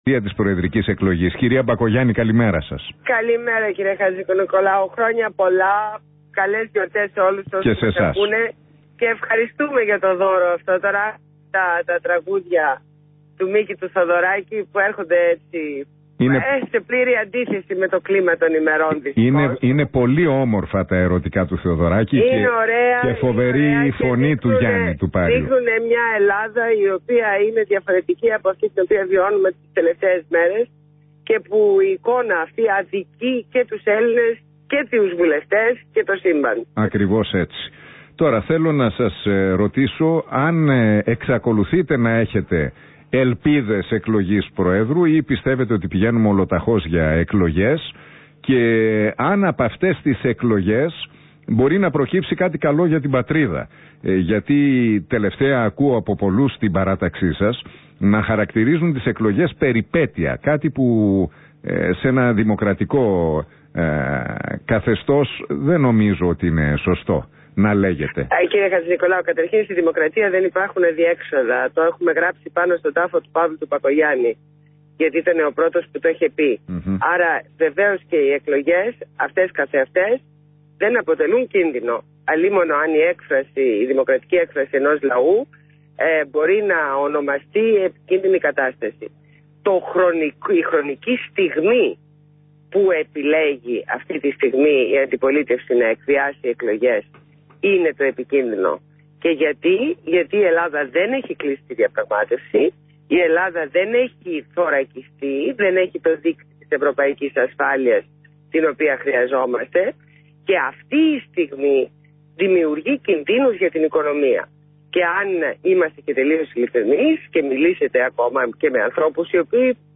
Συνέντευξη στο ραδιόφωνο REAL FM 97.8, στο δημοσιογράφο Ν. Χατζηνικολάου